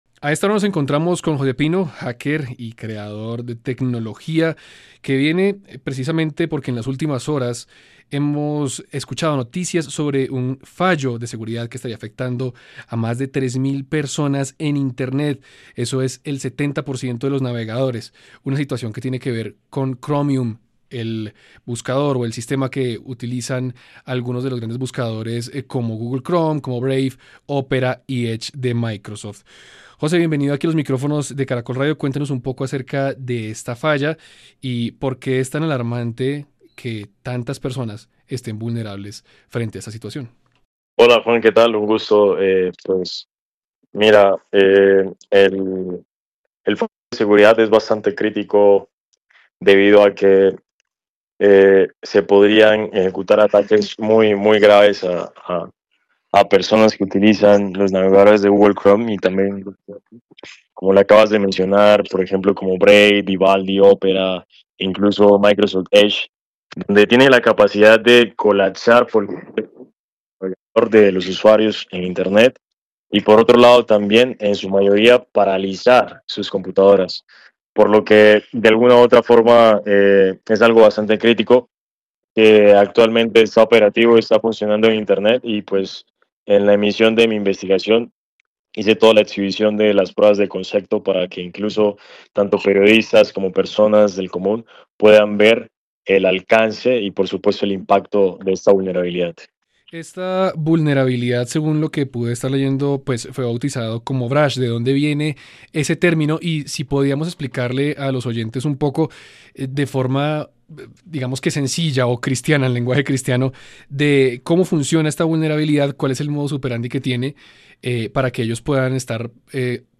En una exclusiva entrevista para Caracol Radio